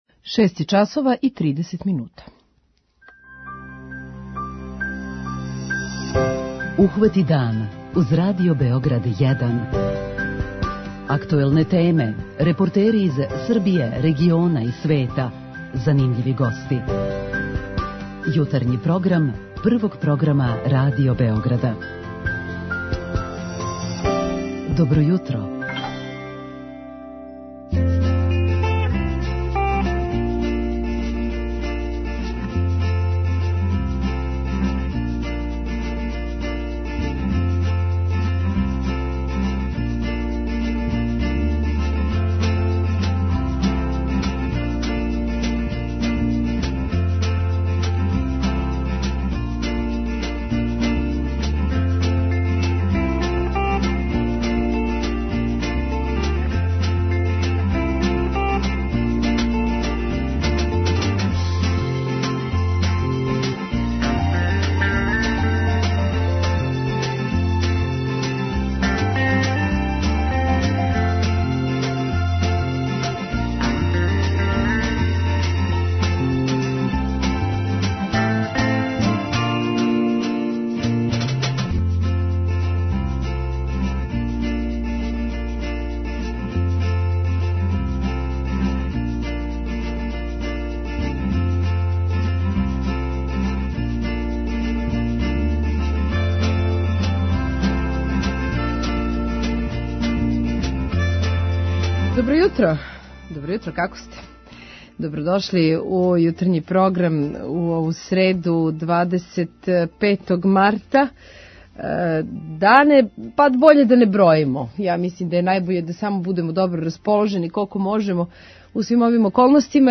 Зашто се одлучио на овакав корак питаћемо га током нашег јутарњег програма, а и са вама у Питању јутра разговарамо о томе да ли су деца преоптерећана задацима које морају да раде док су код куће.